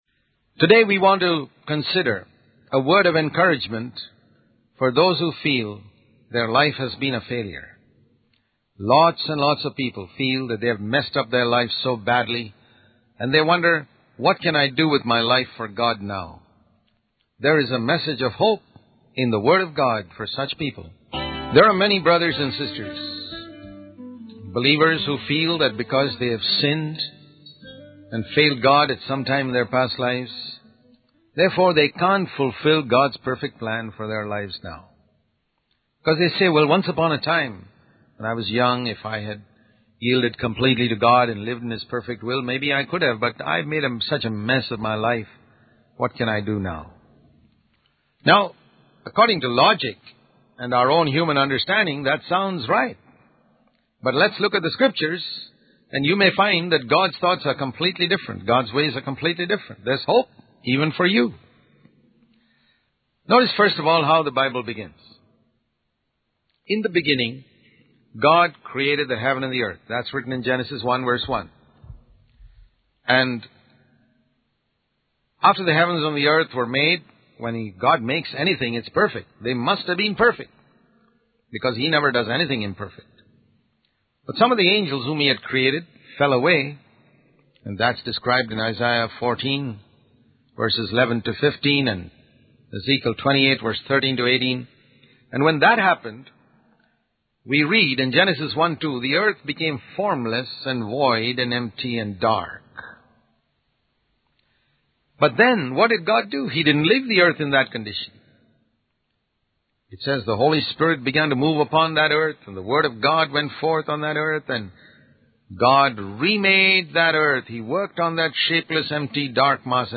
In this sermon, the speaker emphasizes that God can take a person who has made a mess of their life and turn it into something glorious. He uses the stories of the prodigal son and the parable of the laborers to illustrate this point. The speaker encourages listeners not to listen to the lies of the devil and to believe in God's ability to transform their lives.